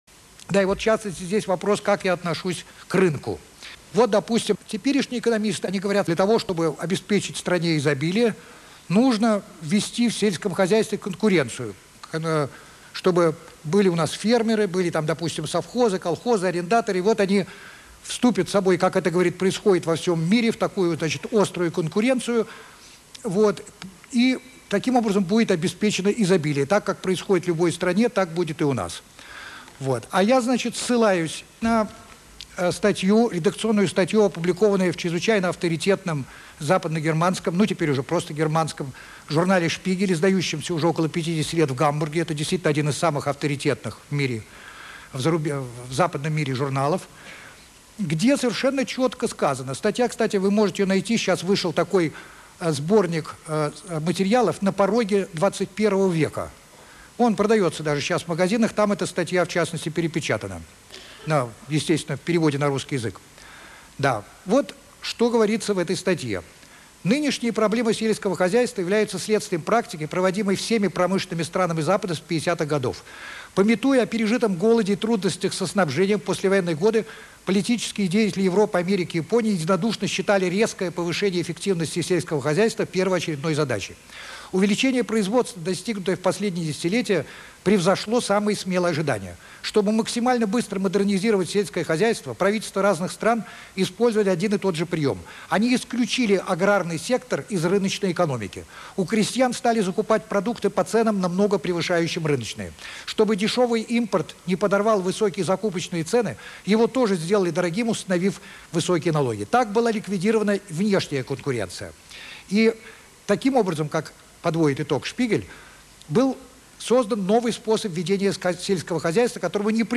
Историк и публицист Вадим Валерианович Кожинов (1930-2001) Запись 18.12.1990 г. в телестудии Останкино.